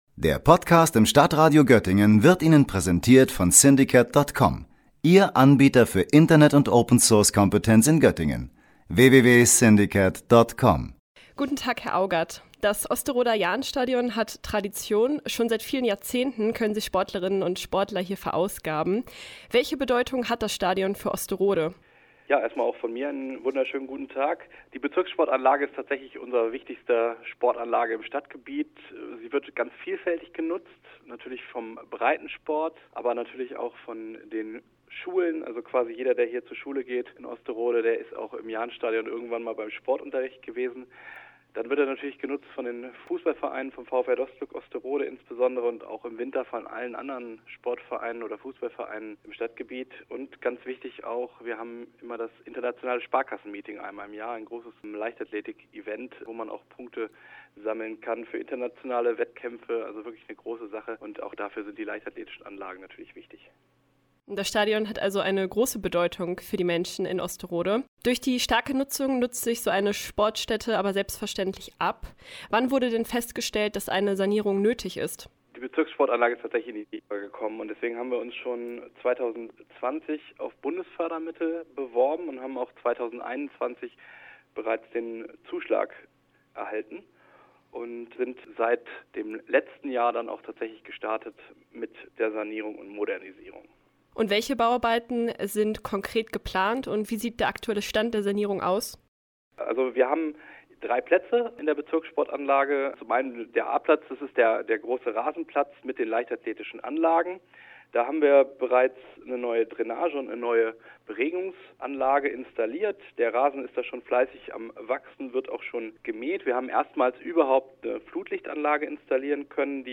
Sie hat mit Jens Augat, dem Bürgermeister von Osterode, gesprochen.